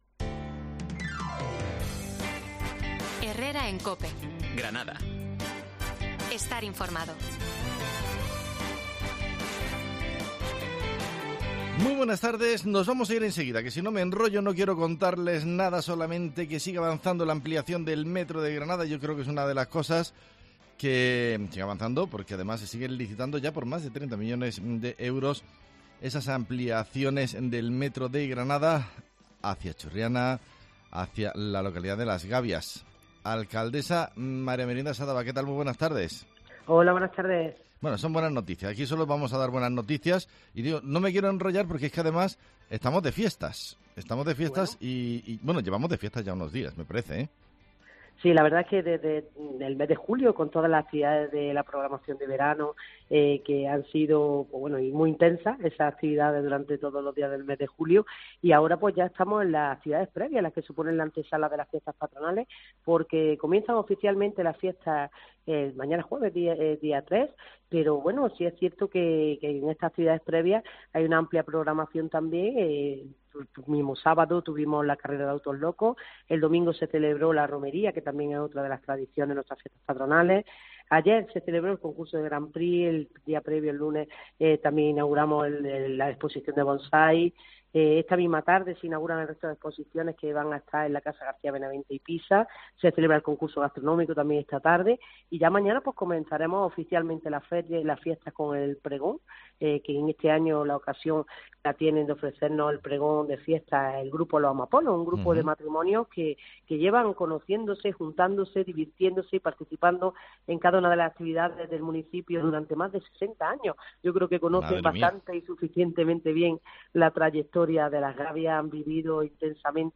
Hablamos con la alcaldesa de las Gabias de las fiestas patronales de ese municipio